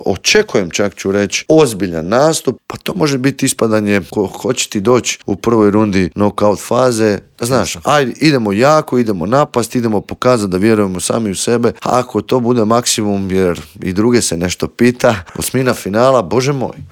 Cijeli Intervju možete pronaći na YouTube kanalu Media servisa.